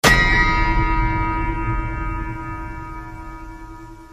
zvuk udara po metalu Meme Sound Effect
zvuk udara po metalu.mp3